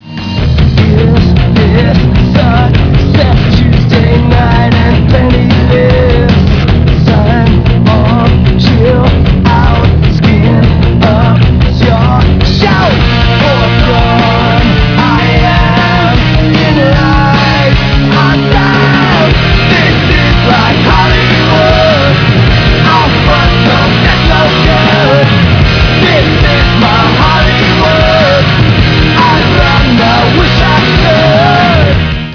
Vocals and Bass
Guitar
Drums